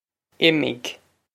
Imigh Im-ig
This is an approximate phonetic pronunciation of the phrase.